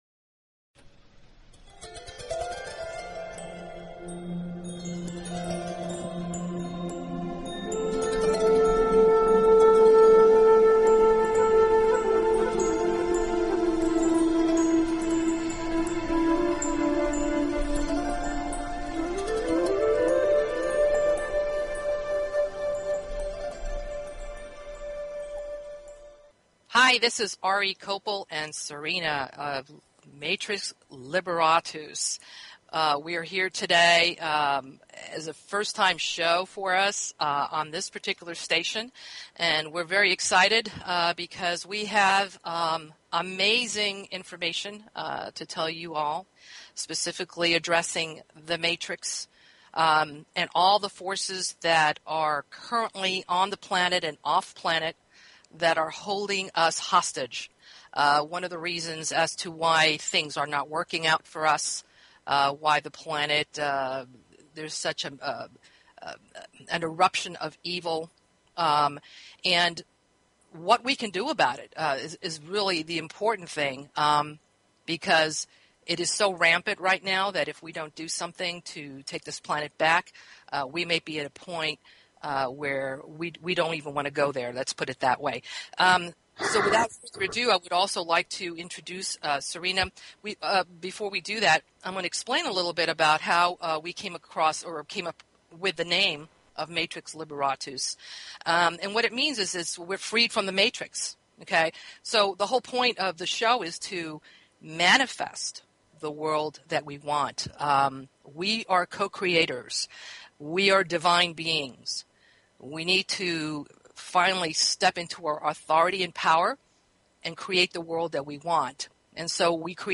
Talk Show Episode, Audio Podcast, Matrix_Liberatus and Courtesy of BBS Radio on , show guests , about , categorized as
Raw & uncensored talk about shattering the Matrix